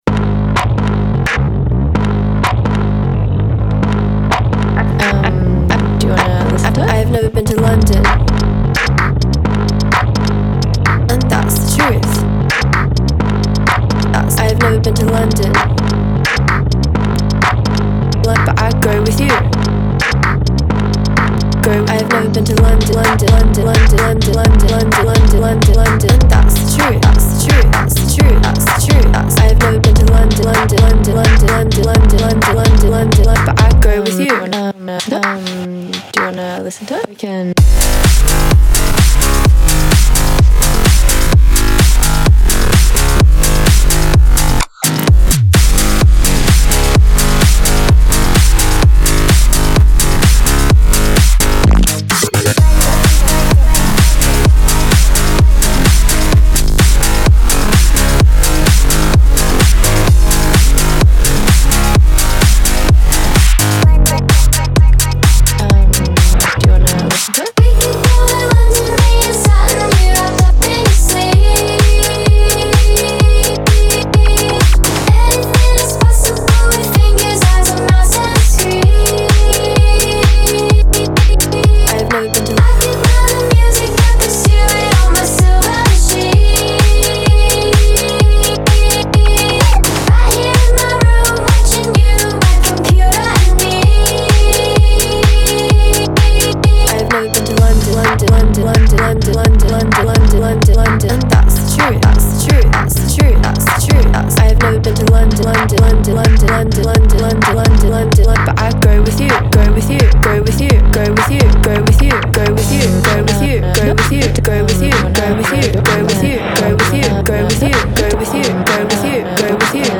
BPM128-128
Audio QualityPerfect (High Quality)
Full Length Song (not arcade length cut)